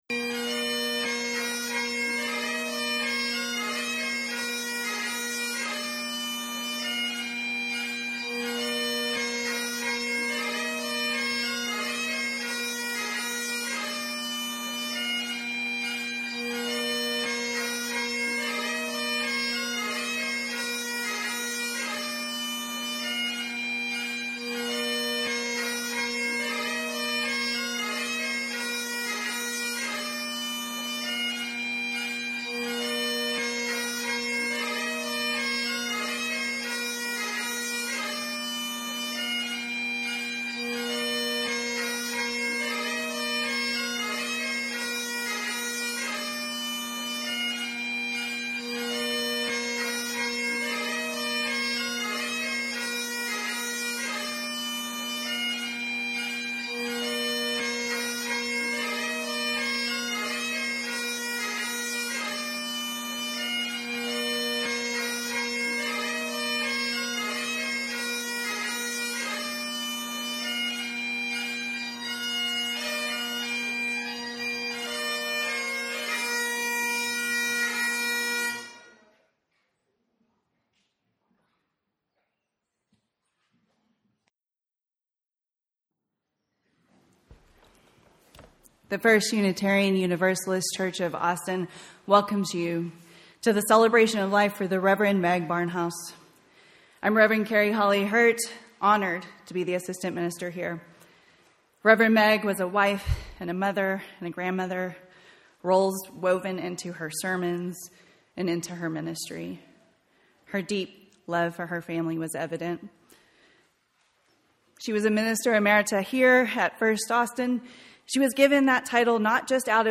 Procession of Ministers “Scotland the Brave,” played by bagpiper